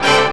JAZZ STAB 8.wav